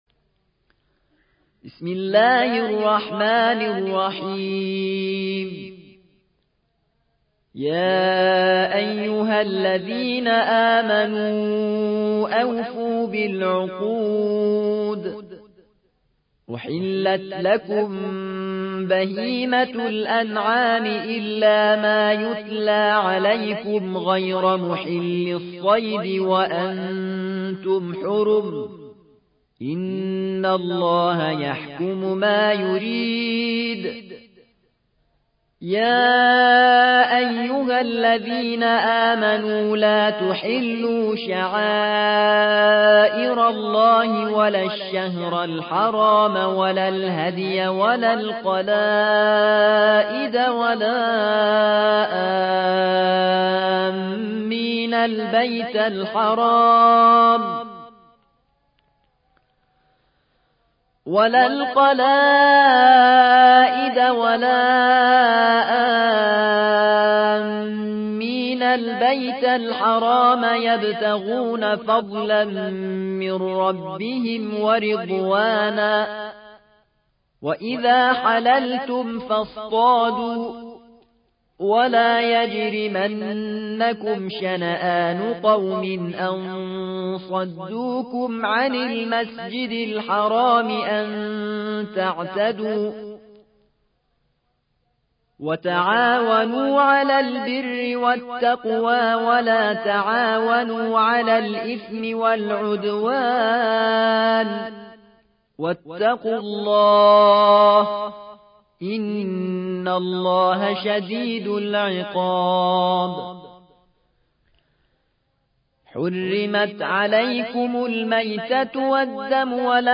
5. سورة المائدة / القارئ